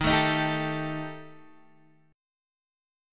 D# Major Flat 5th